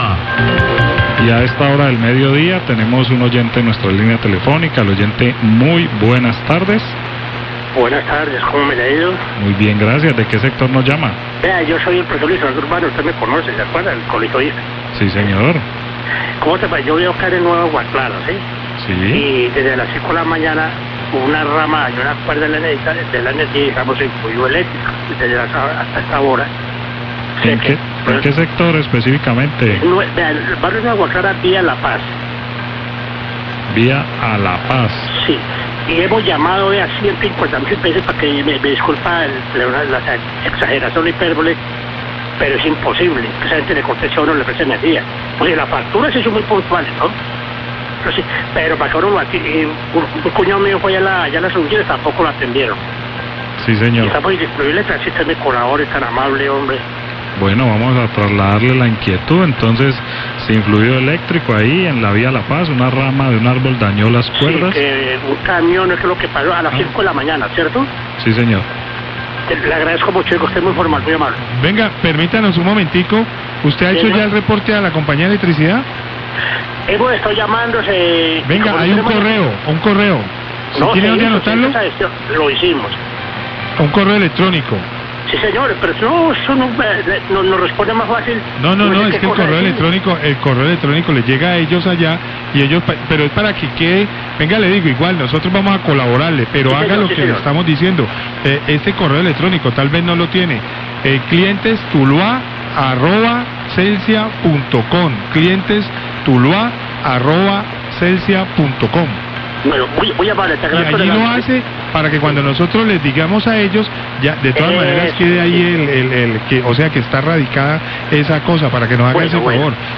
Radio
Oyente reporta que en Aguaclara vía La Paz las ramas de un árbol están interfiriendo con el fluido eléctrico. Manifestó que vía telefónica ha intentado comunicarse con la empresa y no ha sido posible hablar con alguien .